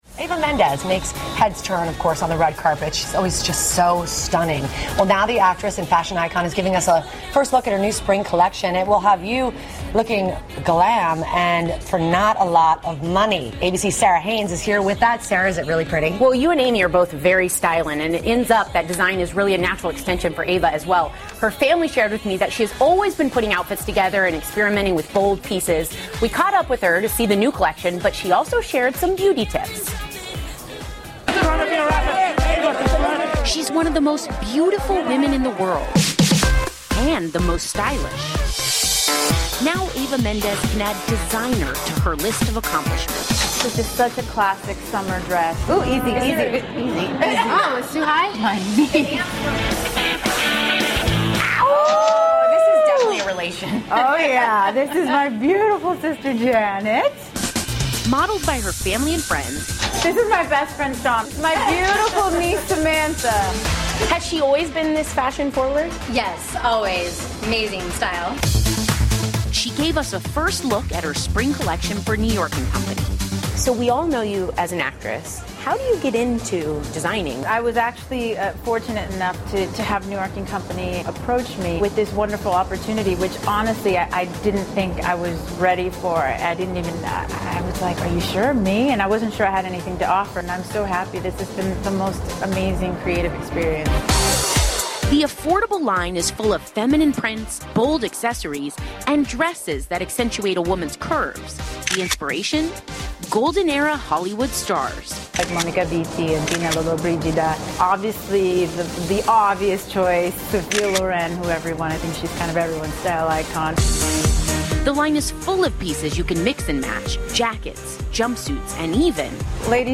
访谈录 2014-04-13&04-15 “性感女神”伊娃门德斯分享时尚技巧 听力文件下载—在线英语听力室